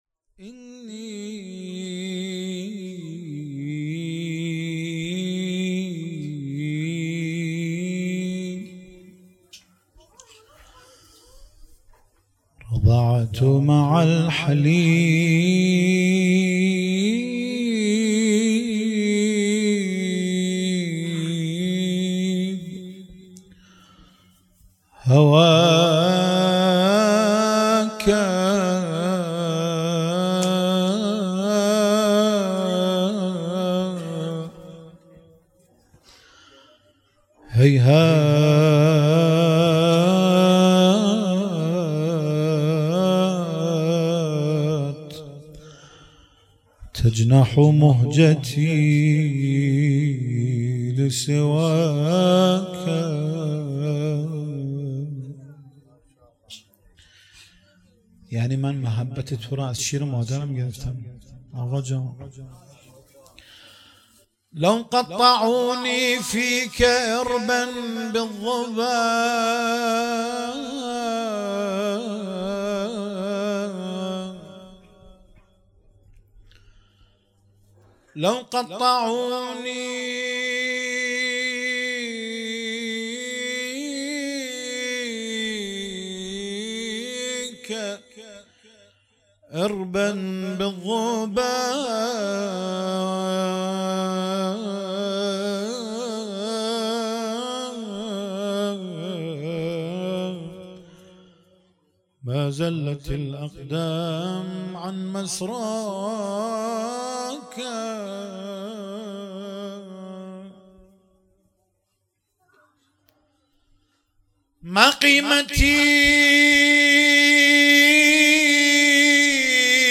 ولادت سرداران کربلا
مدح